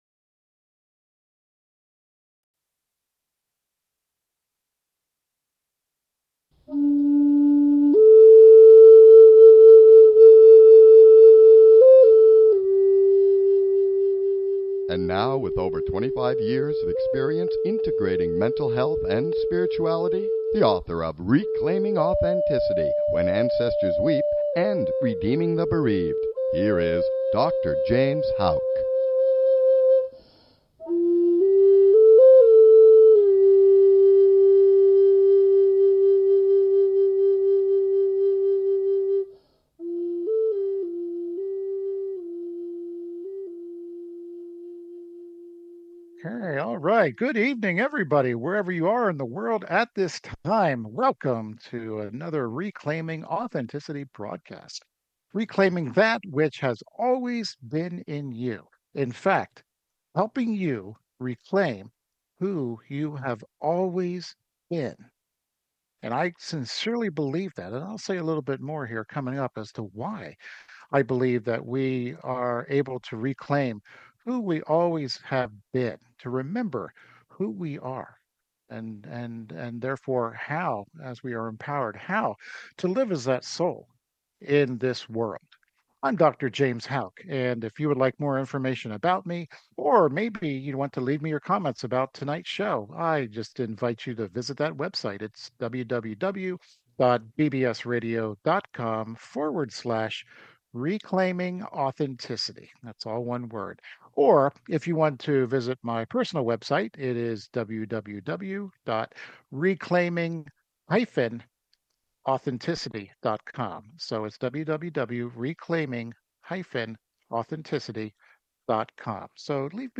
Talk Show Episode, Audio Podcast, Reclaiming Authenticity and Contemplation.